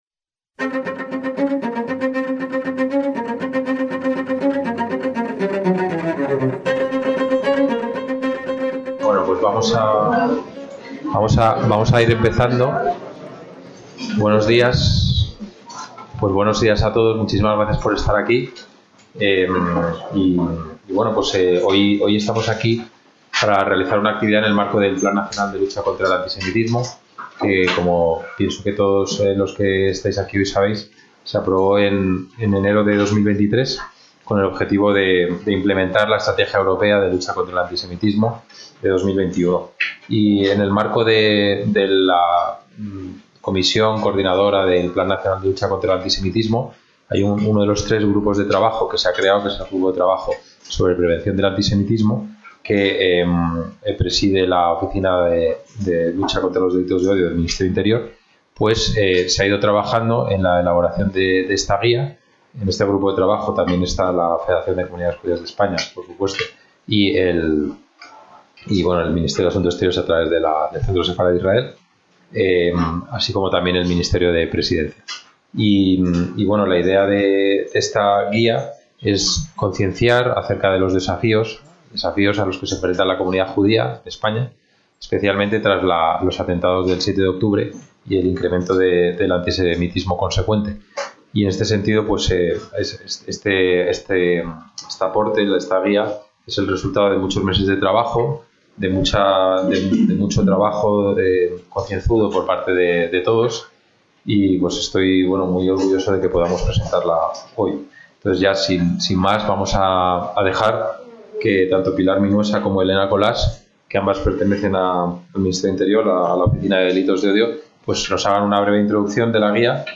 ACTOS EN DIRECTO - El martes 6 de mayo de 2025 se ha presentado en el Centro Sefarad-Israel la Guía práctica sobre judaísmo para los cuerpos y fuerzas de seguridad del estado. Se trata de una herramienta desarrollada por la Federación de Comunidades Judías de España (FCJE), la Oficina Nacional de Delitos de Odio (Ondod) y el Observatorio español del Racismo y Xenofobia (Oberaxe) y el Centro Sefarad-Israel, con el objetivo de que los agentes conozcan la realidad judía española a través de sus costumbres, simbología, festividades, rituales, etc.